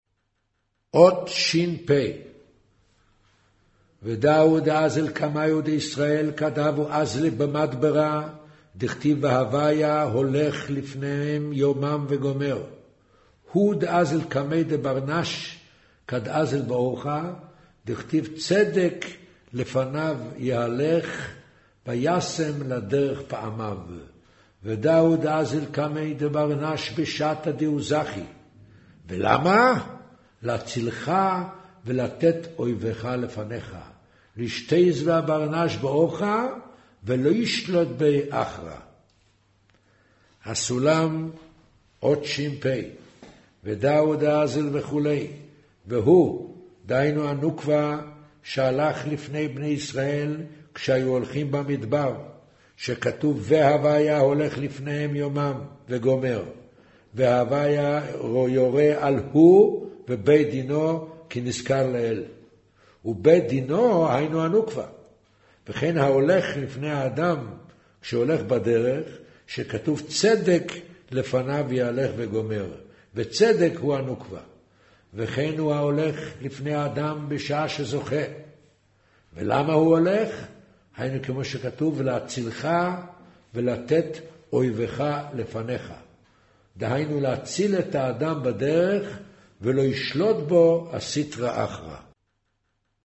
קריינות זהר, פרשת נח, מאמר ויאמר ה' הן עם אחד